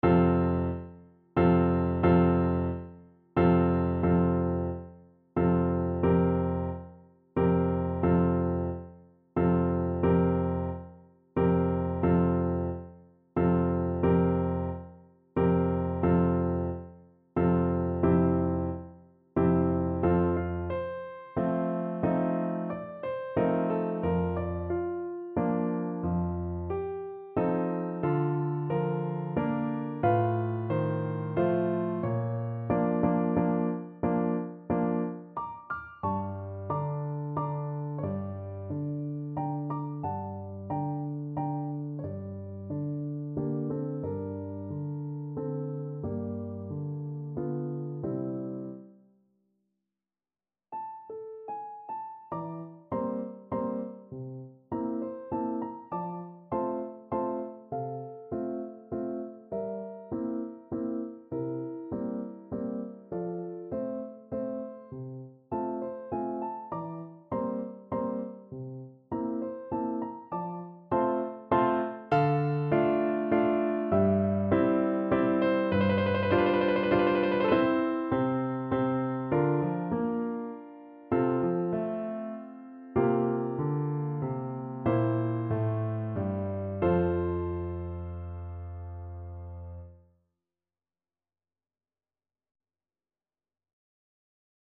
Play (or use space bar on your keyboard) Pause Music Playalong - Piano Accompaniment Playalong Band Accompaniment not yet available reset tempo print settings full screen
3/4 (View more 3/4 Music)
F major (Sounding Pitch) C major (French Horn in F) (View more F major Music for French Horn )
~ = 90 Allegretto moderato
Classical (View more Classical French Horn Music)